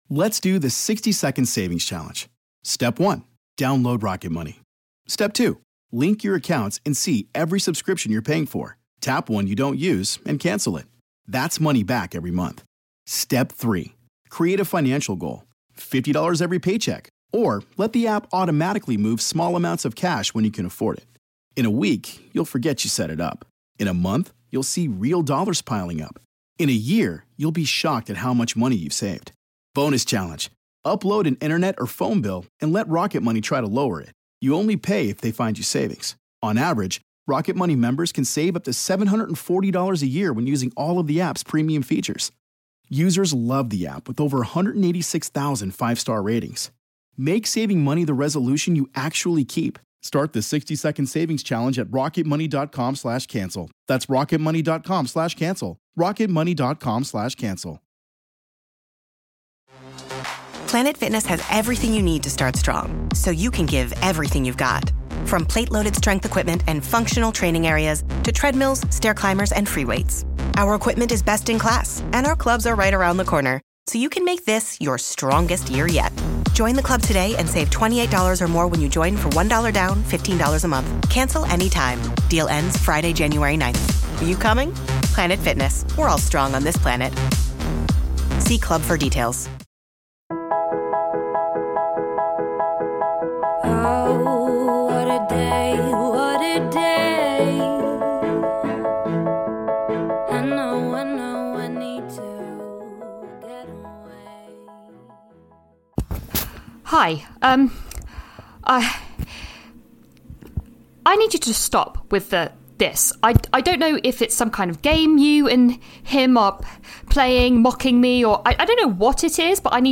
Lydia is Patsy Ferran Meg is Mandeep Dhillon